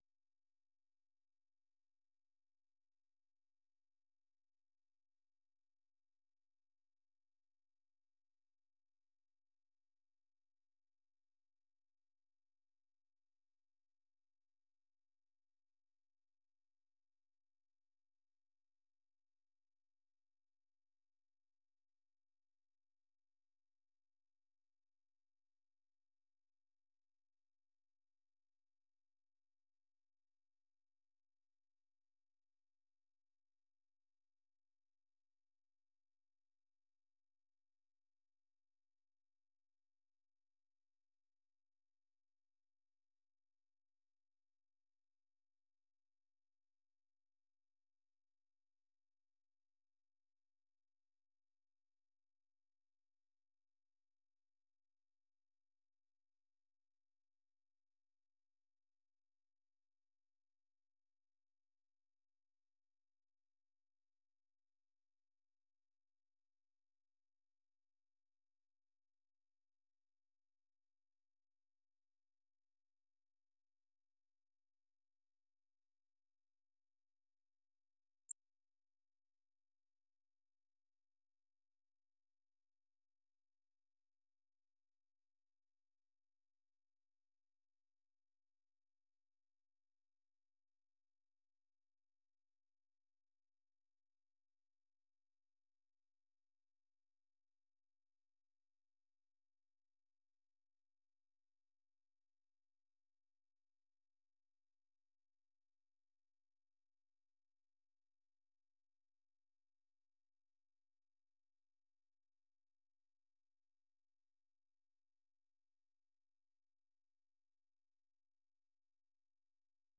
Listen Live - 생방송 듣기 - VOA 한국어